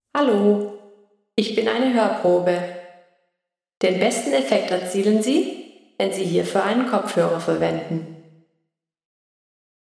Hörprobe ohne Absorber
Hoerprobe-ohne-Absorber.mp3